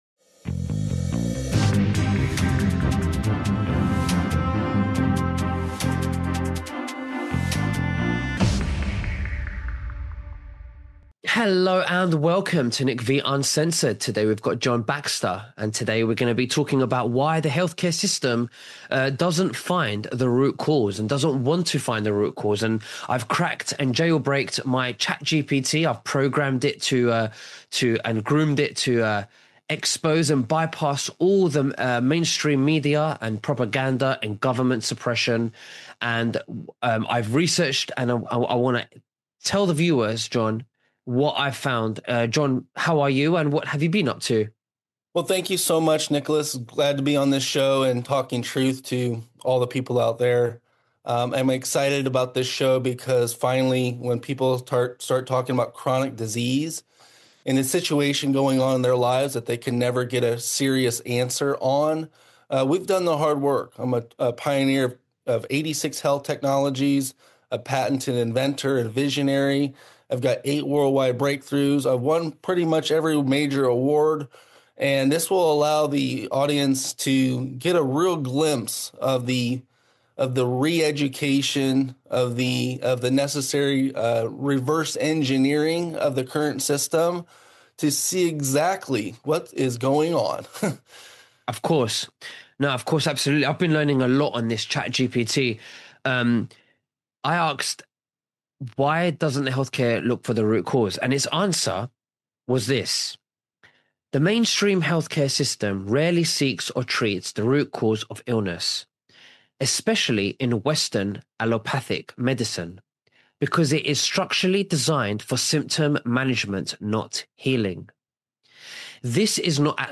Live Shows